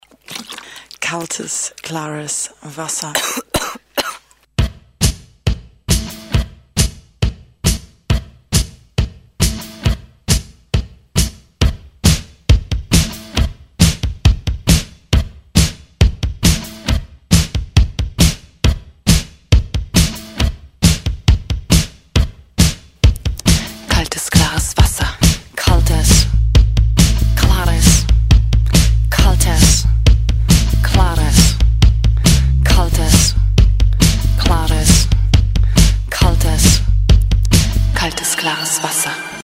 (A/V) sa, 00:45 - 01:45, mainfloor